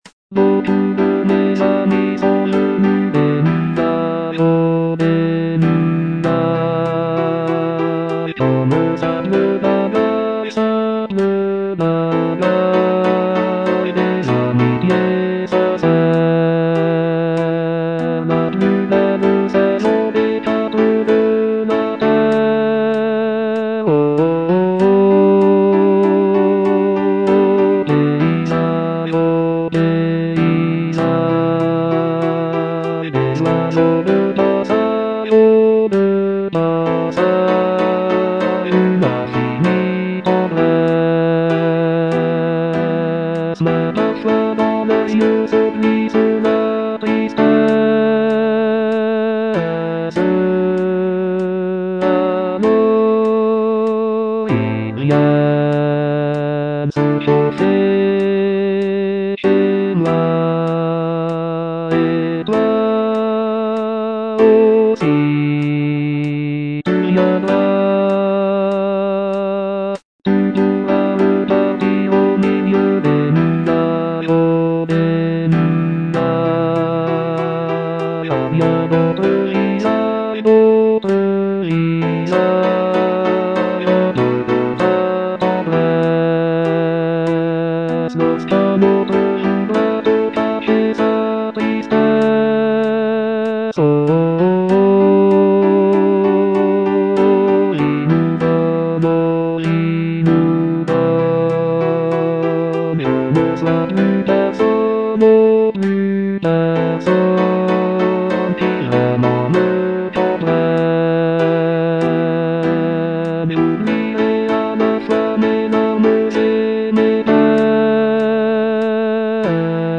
Bass I (Voice with metronome)
a charming piece for choir